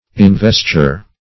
Search Result for " investure" : The Collaborative International Dictionary of English v.0.48: Investure \In*ves"ture\ (?; 135), n. Investiture; investment.